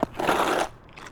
Zombie Footstep Concrete 2 Sound
horror